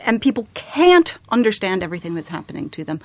Now, let’s have some examples of can’t  in American English:
You’ll notice that the vowel is not the same as the one used by British speakers.
In this recording, the /t/ is clearly heard, but don’t take it for granted because Americans drop it too.